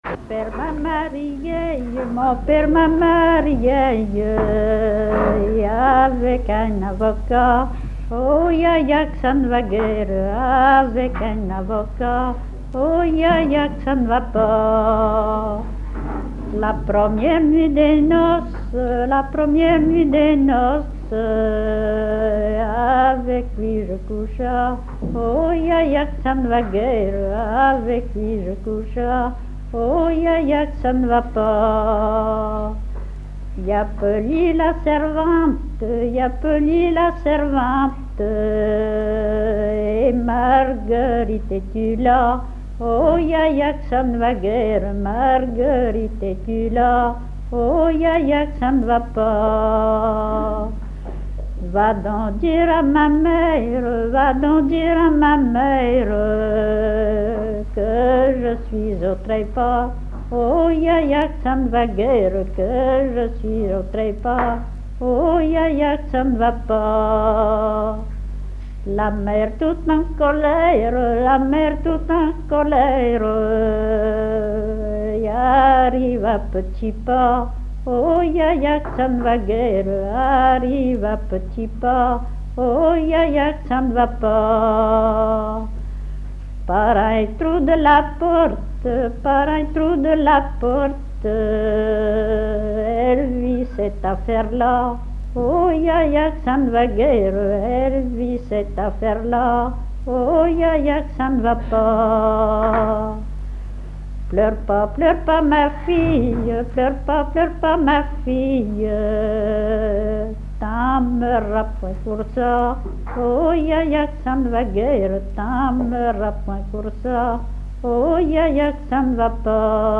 Genre laisse
à la salle d'Orouët
Pièce musicale inédite